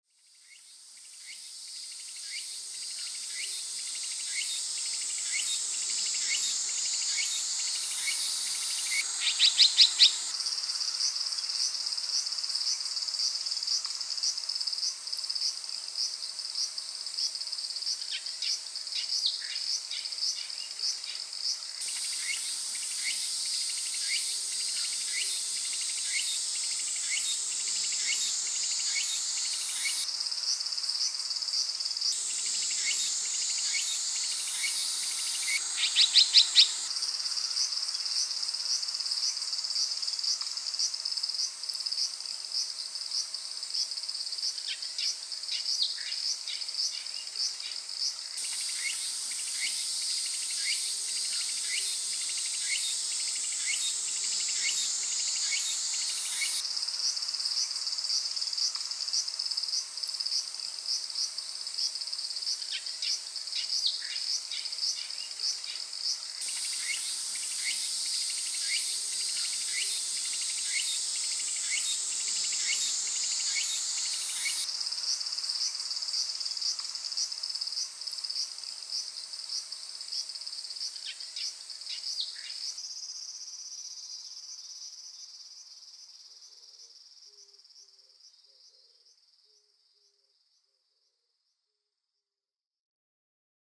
2025年8月初旬に「茨城県戸頭」での蝉とツクツクボウシです。